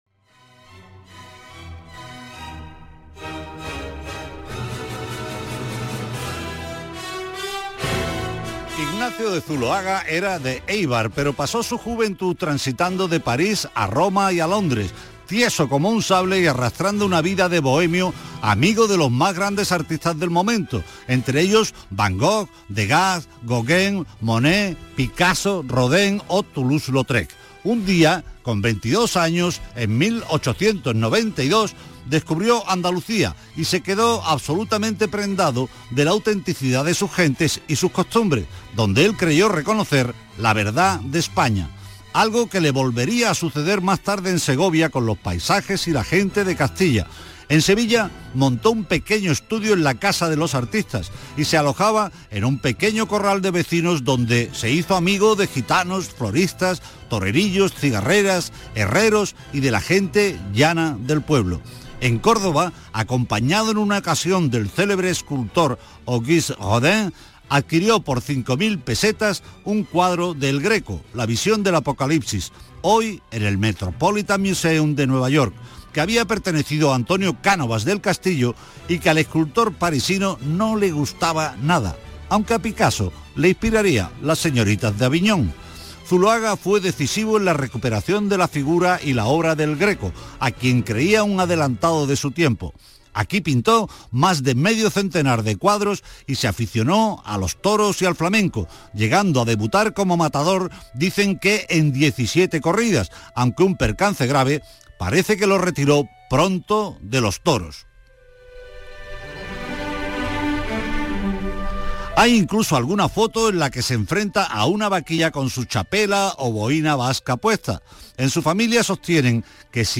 Aquí os dejo mi intervención en el programa de Radio Andalucía Información, «Patrimonio andaluz» del día 26/06/2022